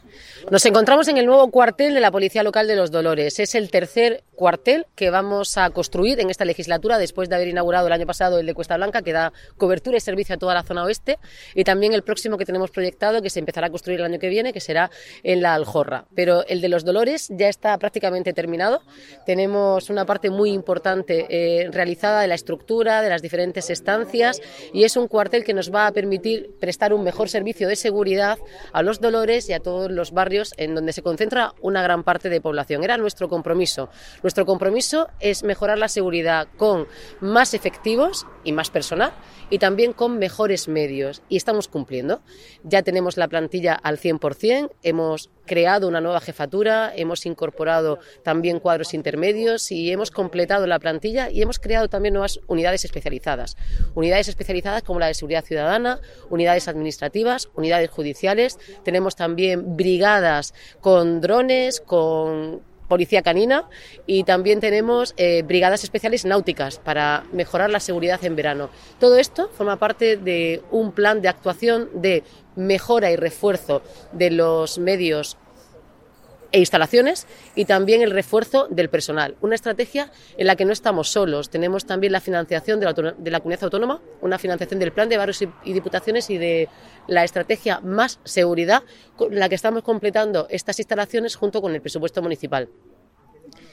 Enlace a Declaraciones de la alcaldesa, Noelia Arroyo, durante la visita obras nuevo cuartel Los Dolores